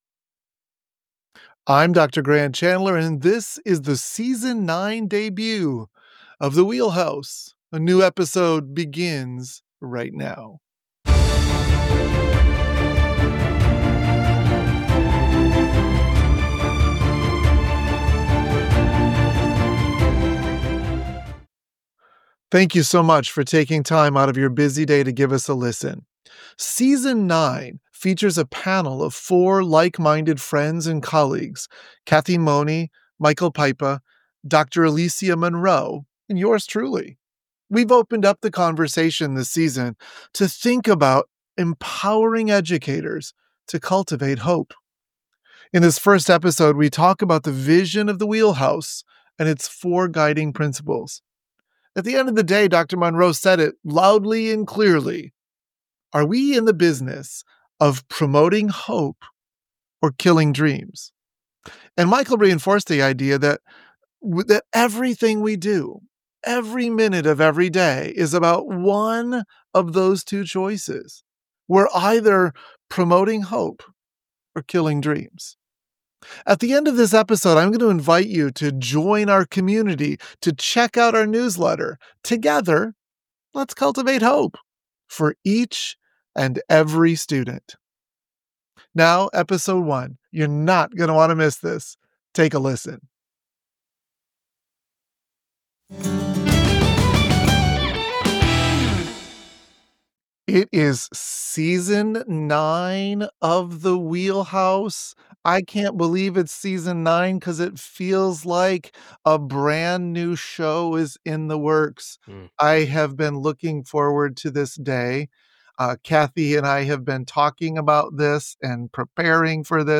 We convene a panel of esteemed colleagues